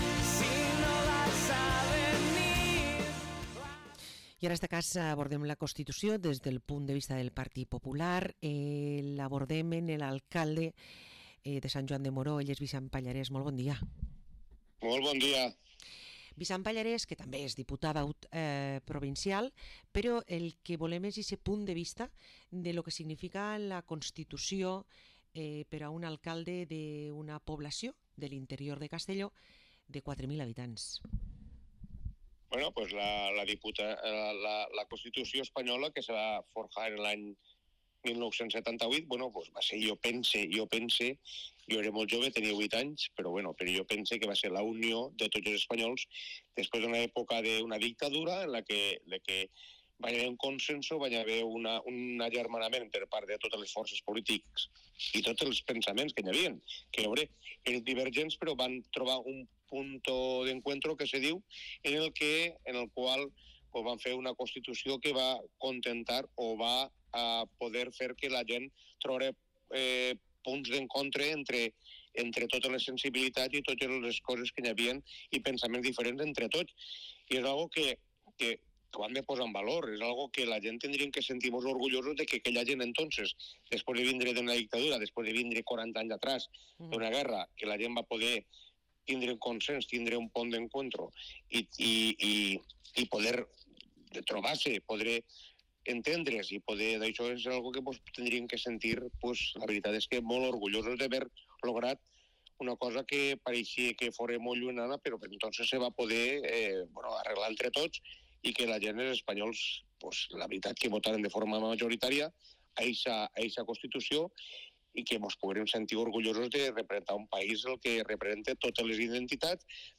Parlem amb Vicent Pallarés, alcalde de Sant Joan de Moró